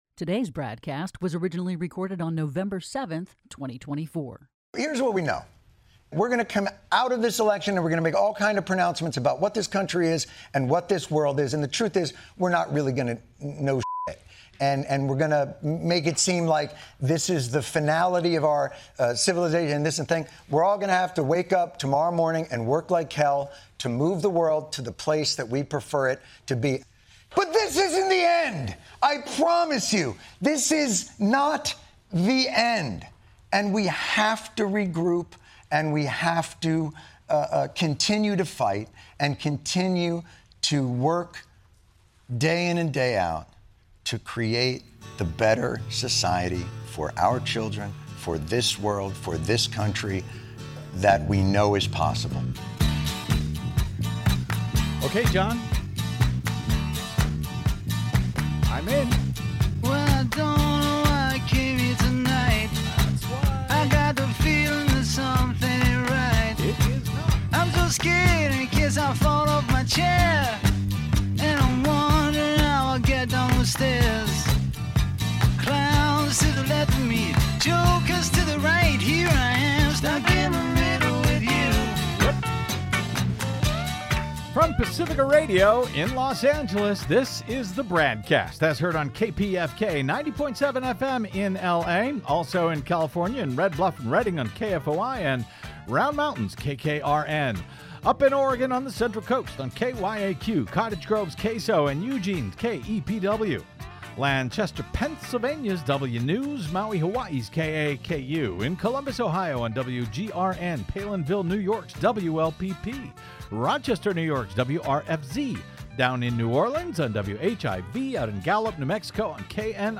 investigative interviews, analysis and commentary